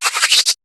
Cri de Sovkipou dans Pokémon HOME.